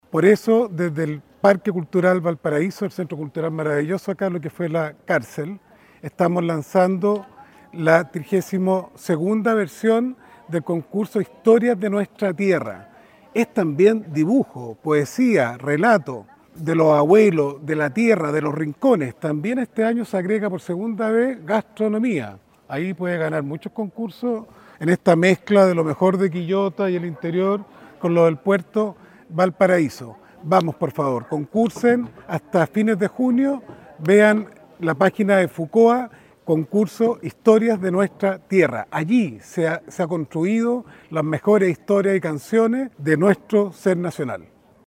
Desde el puerto el ministro Valenzuela invitó a participar de este concurso nacional.